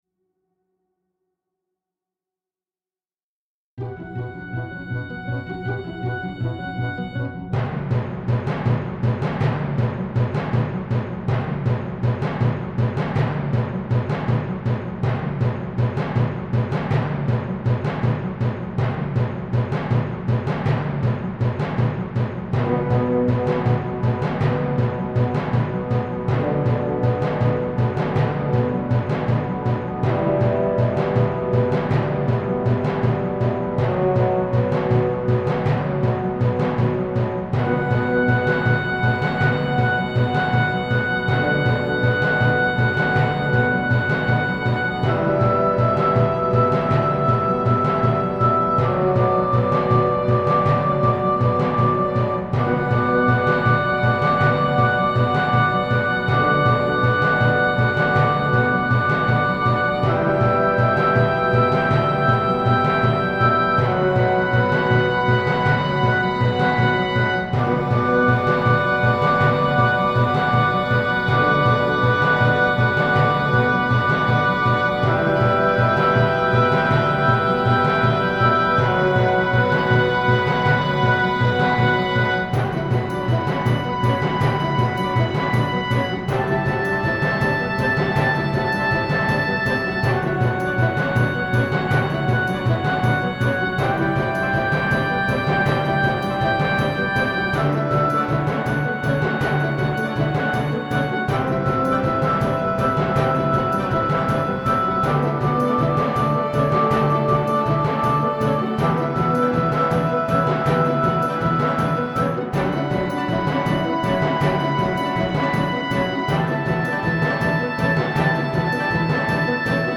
Von minimalistisch bis orchestral.